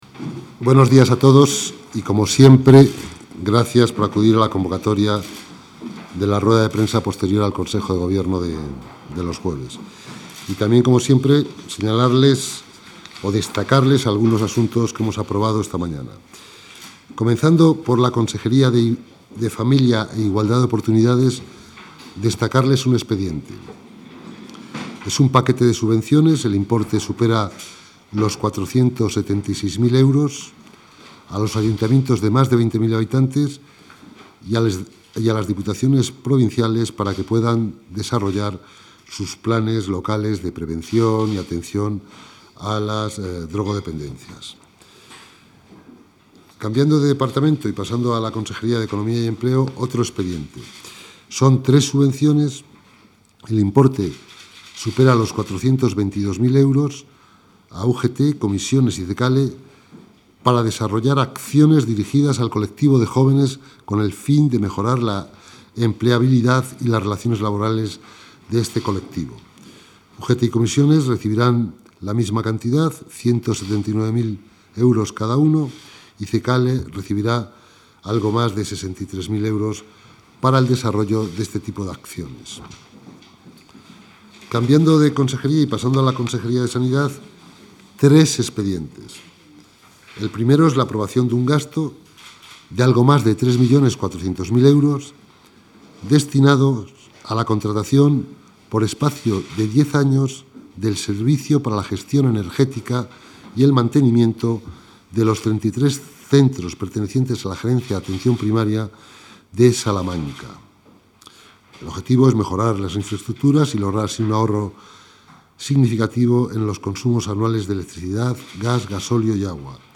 Audio consejero.
Consejo de Gobierno del 10 de julio de 2014.